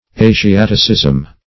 Asiaticism \A`si*at"i*cism\, n. Something peculiar to Asia or the Asiatics.